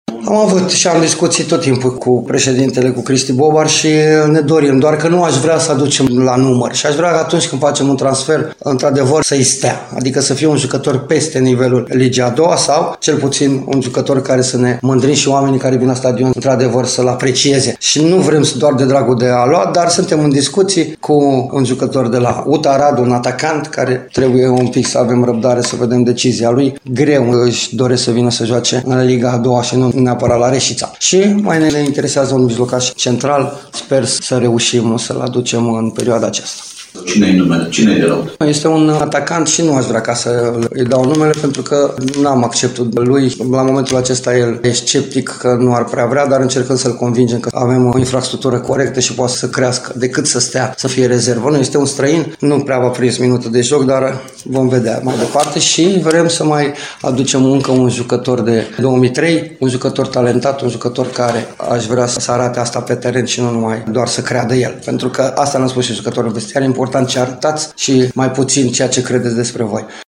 Flavius Stoican – pentru declarația pe larg, acționați butonul „Redare” al fișierului AUDIO de mai jos